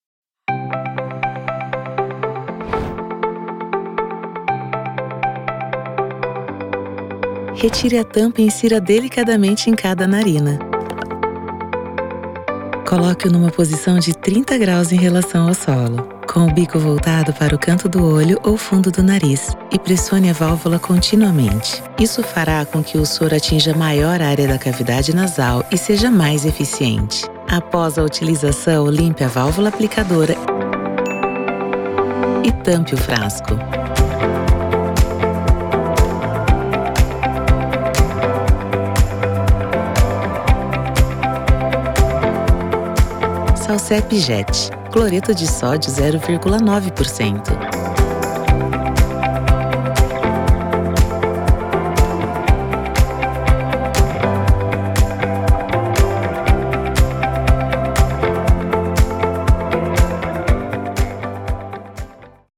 Vídeos Explicativos
Trabalho em estúdio próprio totalmente equipado e entrego áudio de alta qualidade, com flexibilidade para atender necessidades exclusivas da sua marca, negócio ou projeto. Minha voz é versátil, polida e neutra, com interpretações personalizadas para seus objetivos.
Contralto